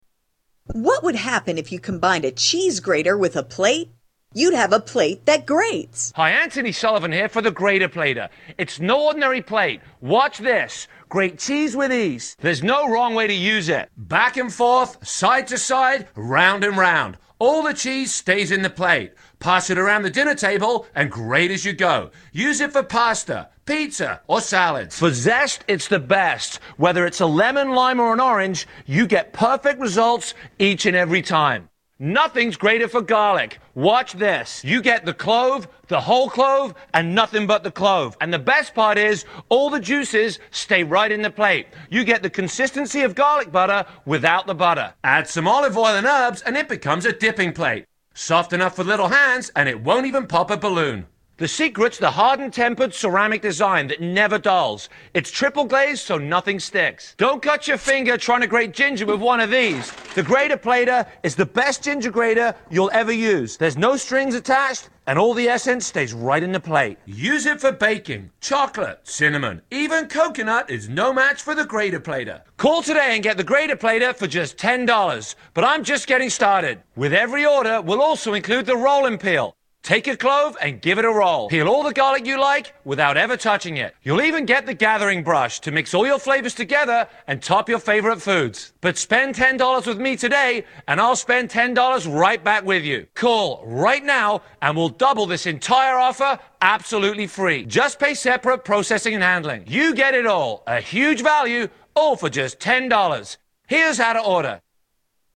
Grater Plater Commercial
Tags: Media Anthony Sully Sullivan Anthony Sullivan Anthony Sullivan Ads Anthony Sullivan Infomercials